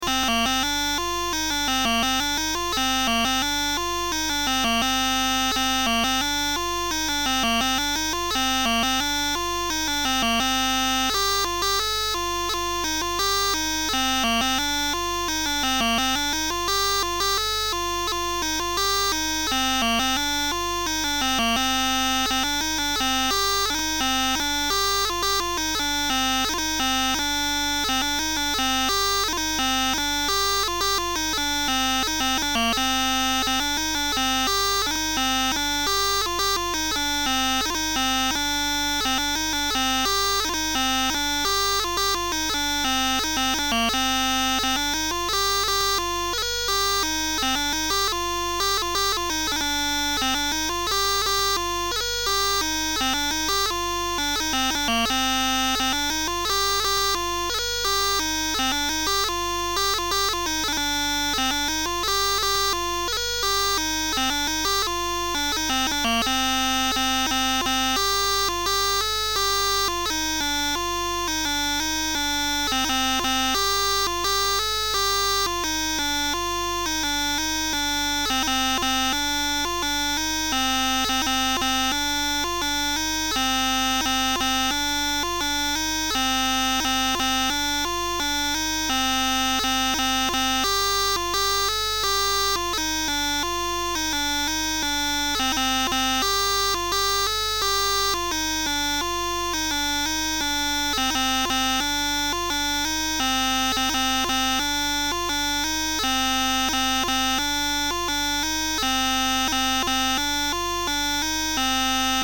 Andro suite